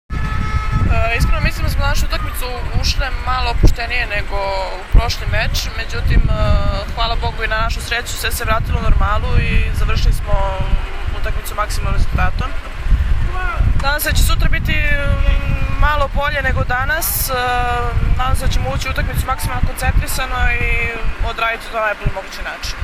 IZJAVA MILENE RAŠIĆ